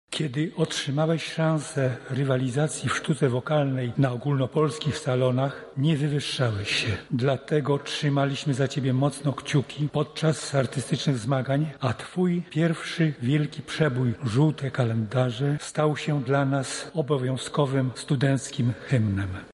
jeden z kolegów ze studiów
Pogrzeb Piotra Szczepanika